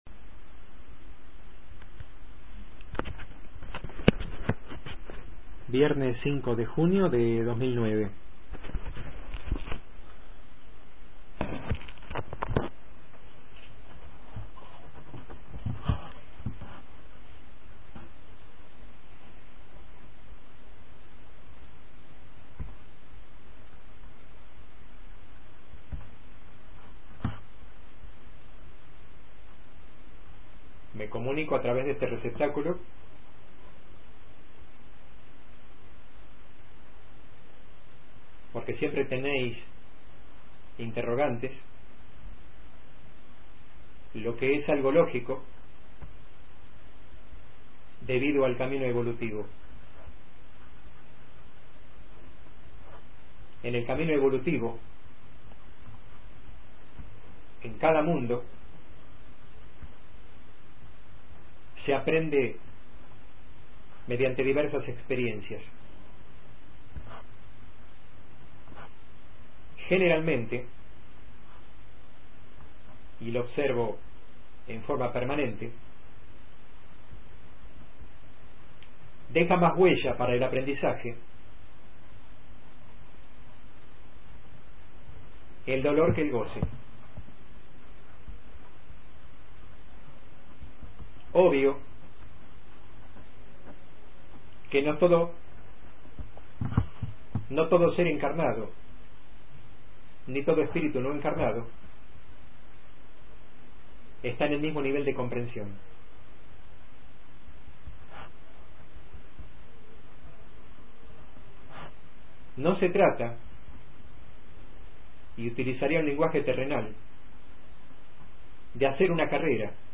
Entidad que se presentó a dialogar : Abba, el Todo.